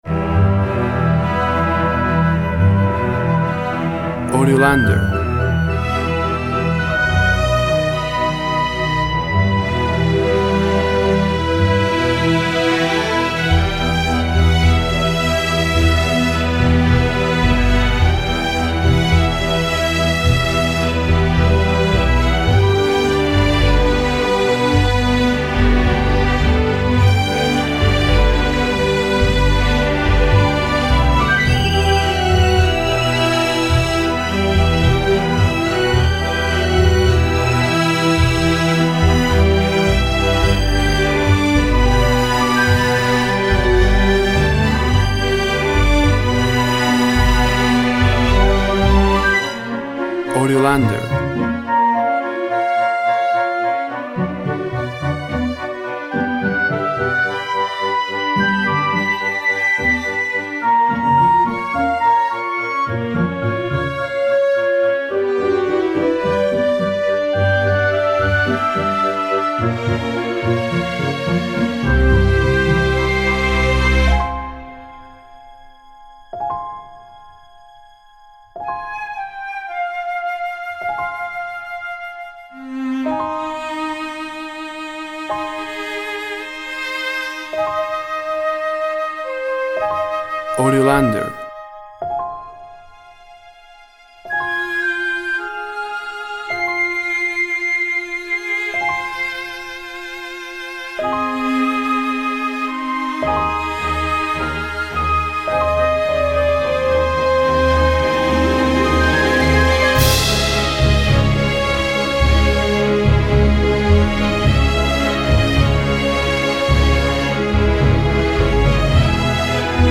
WAV Sample Rate 16-Bit Stereo, 44.1 kHz
Tempo (BPM) 107